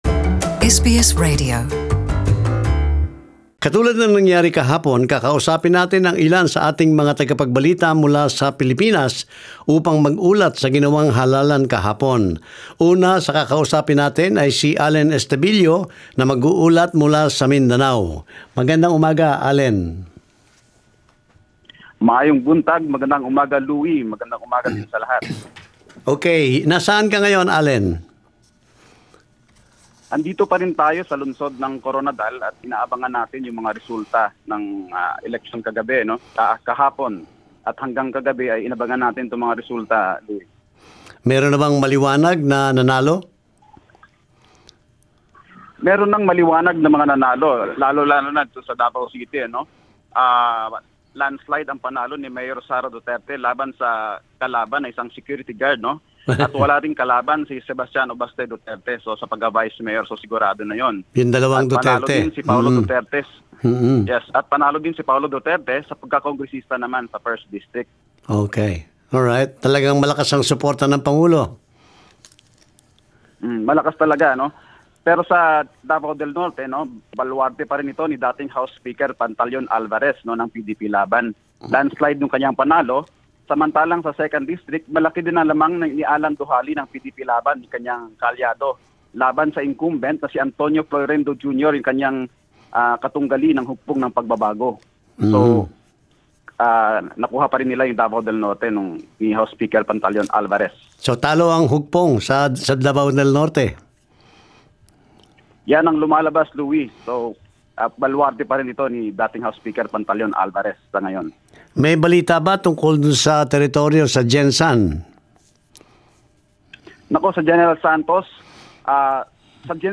Nagdaos ng pambansang halalan ang Pilipinas noong Lunes, ika 13 ng Mayo 2019. Isina-himpapawid ang mga pansamantalang resulta ng halalan mula sa Mindanao, Visayas at Metro Manila, ng tatlo sa mga tagapag-balita ng programang SBS Filipino.